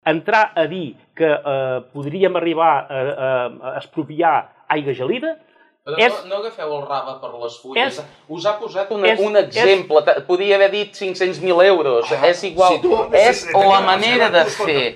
I reacció de l’alcalde durant el debat electoral Palafrugell 2019.